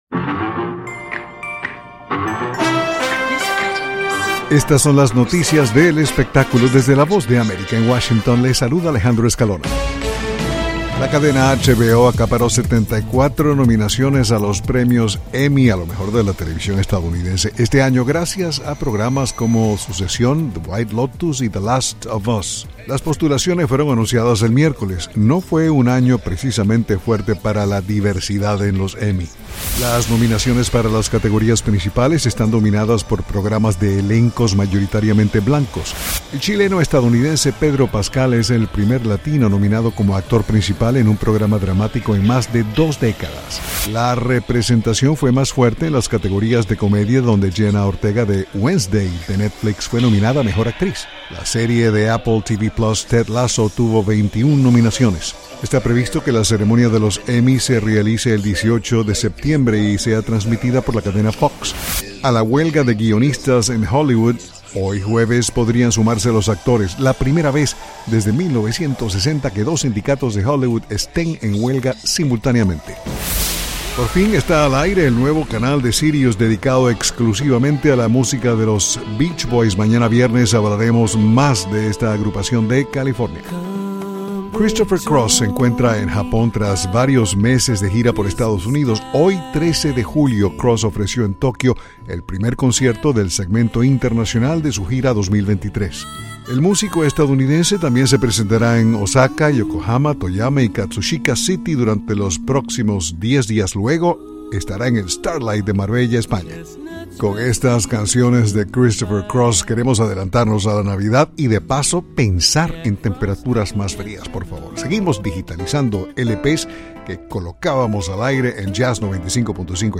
Son las noticias del espectáculo